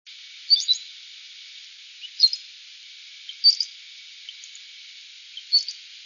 Cowbird
Lincoln Park, Manville, 4/7/00 (24kb), high pitched "weee-titi"
I recorded this Cowbird (wave533) while it was sitting calmly on a telephone pole.  The cadence of this song reminds me of the cardinals "whir-a-chee", just a little faster, and much higher in pitch.  There is a quality to this sound that reminds me of electricity fired off of electric train wires or a sneaker twisting on a basketball court..
cowbird533.wav